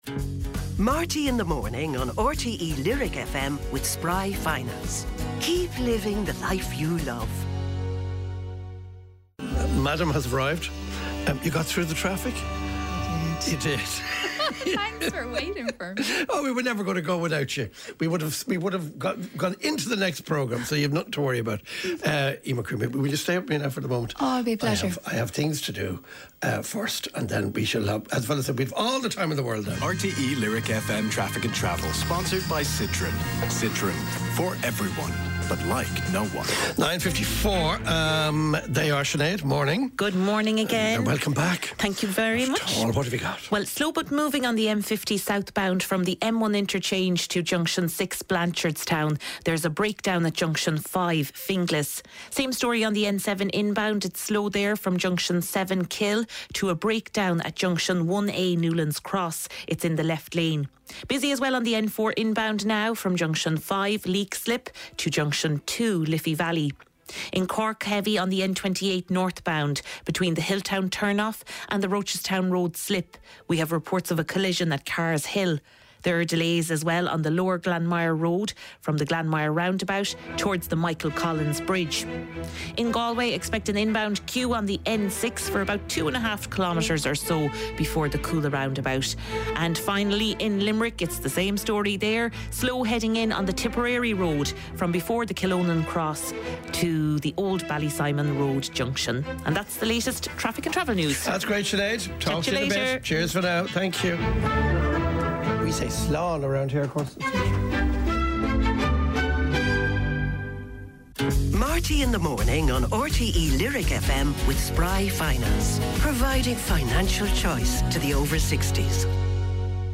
Sem a necessidade de instalar ou se inscrever The lovely Eimear Quinn is in Studio.
Highlights and special guest interviews and performances from Marty Whelan's breakfast show on RTÉ Lyric FM.